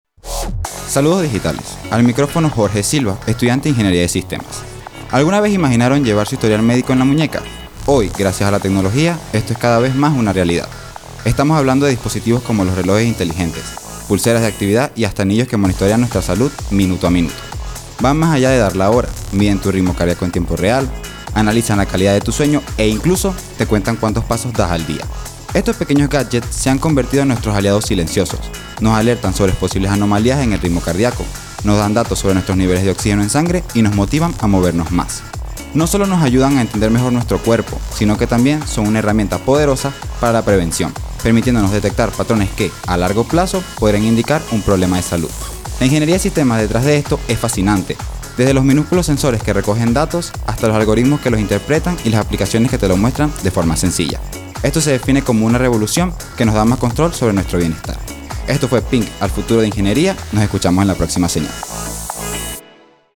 Estudiantes de la carrera de Ingeniería de Sistemas